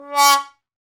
Index of /90_sSampleCDs/Roland LCDP12 Solo Brass/BRS_Trombone/BRS_TromboneMute